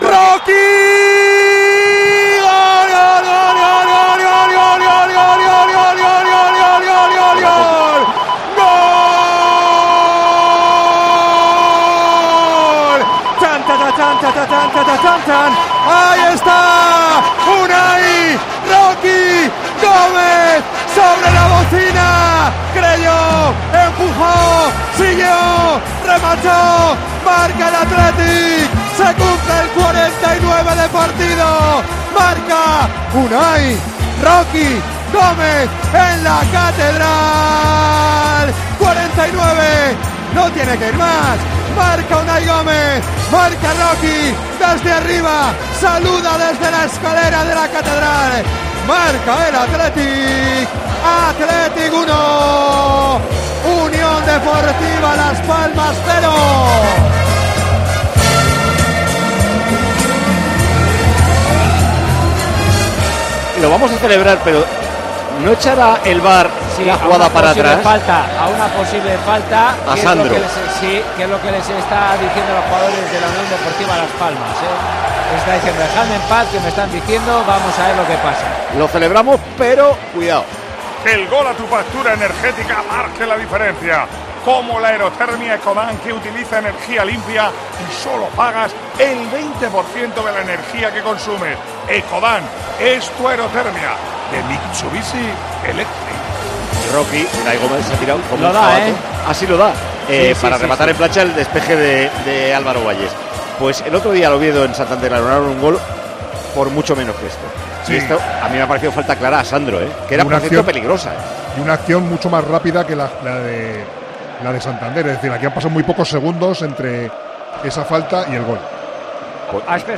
Micrófono de COPE en San Mamés